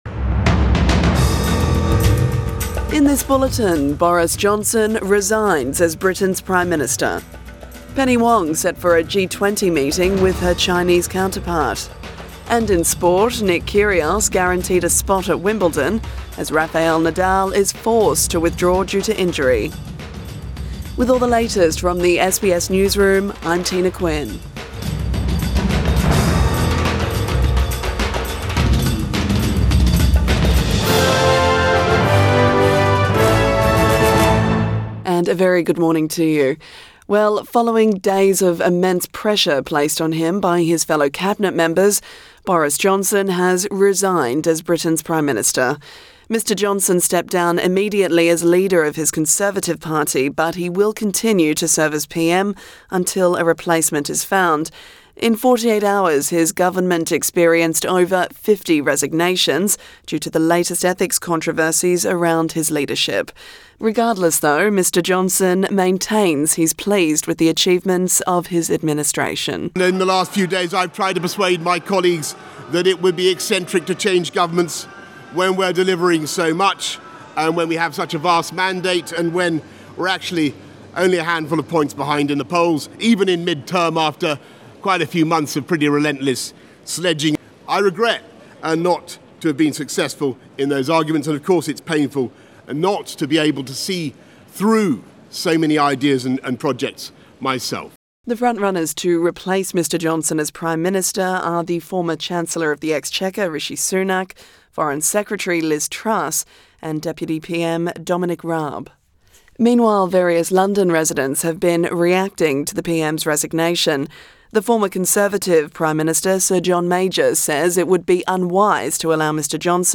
AM bulletin 8 July 2022